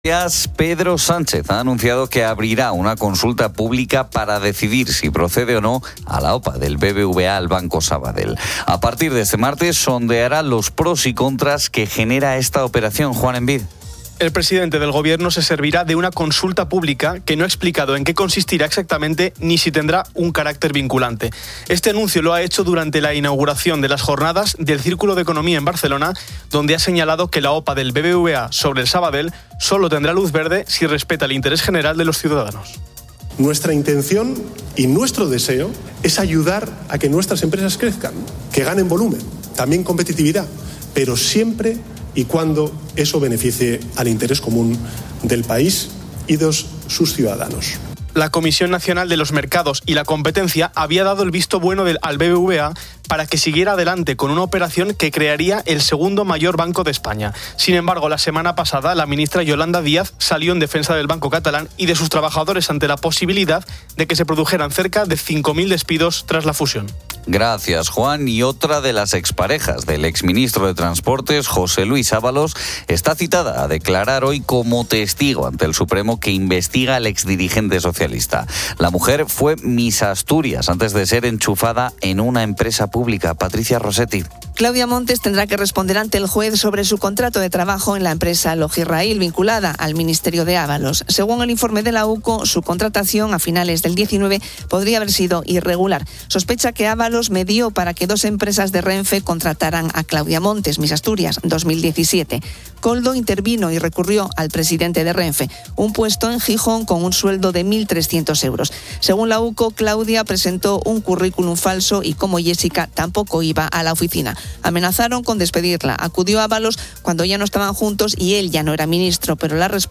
Noticias. Pedro Sánchez ha anunciado que abrirá una consulta pública para decidir si procede o no a la OPA del BBVA al Banco Sabadell.